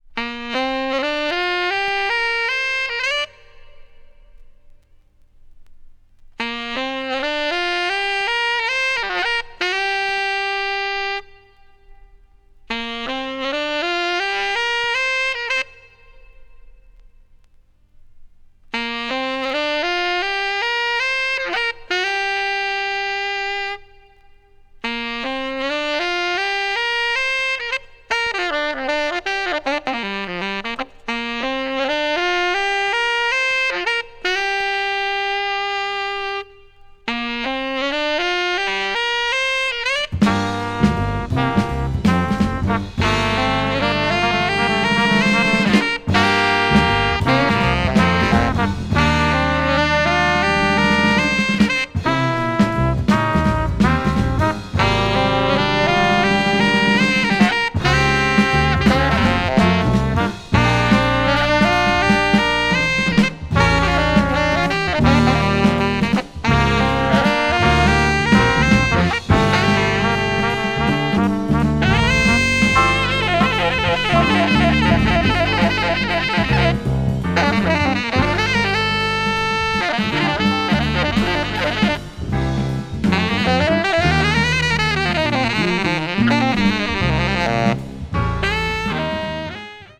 modal jazz   post bop   spritual jazz